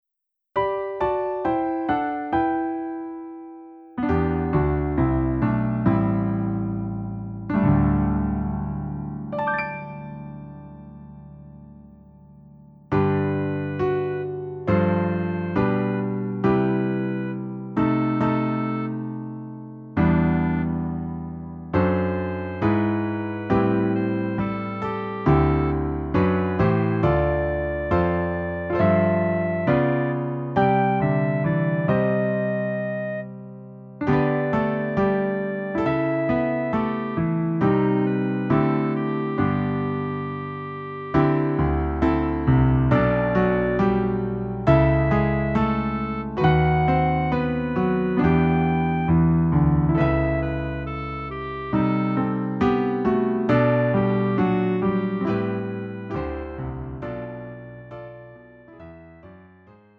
음정 원키 3:45
장르 가요 구분